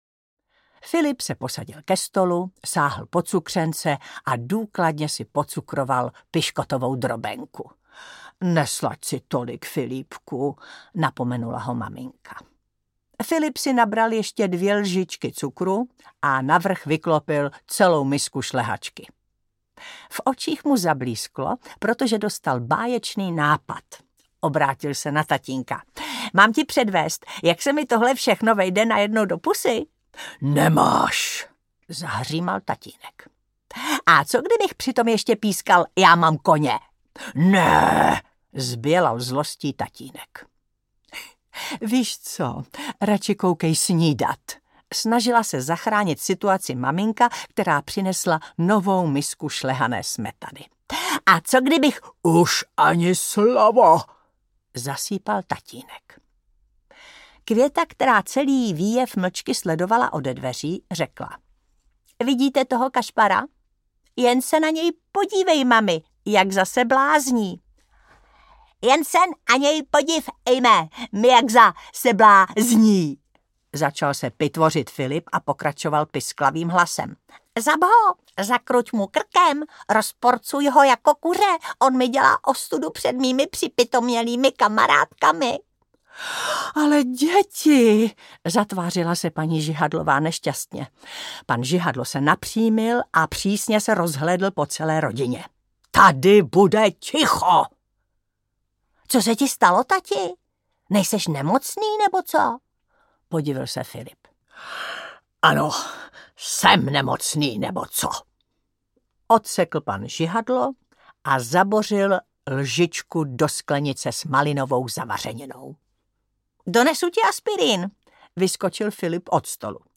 Haló, paní Láryfáry audiokniha
Ukázka z knihy
Čte Taťjana Medvecká.
Vyrobilo studio Soundguru.